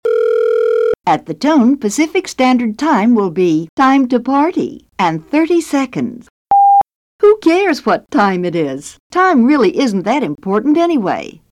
Jane Barbe (The Bell System Time Lady)
Jane's Funny Time Recording